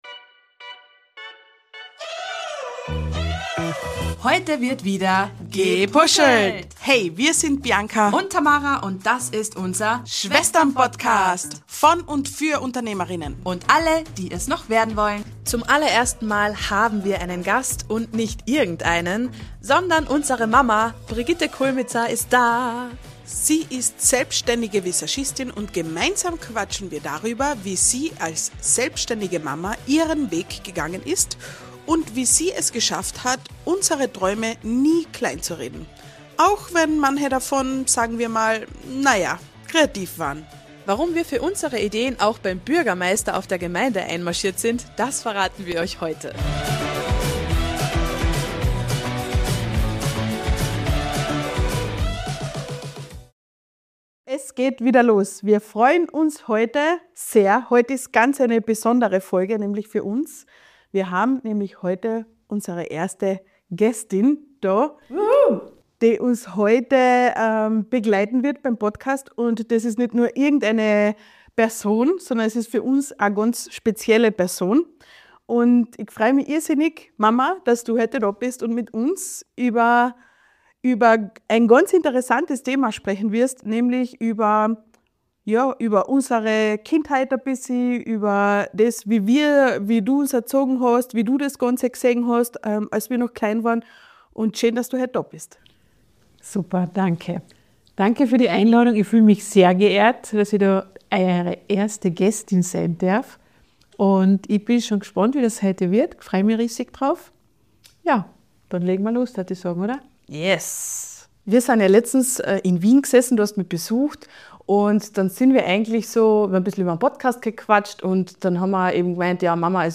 Ein sehr persönliches Gespräch über Mut, Verantwortung und den Weg einer Frau, die ihrer Zeit voraus war.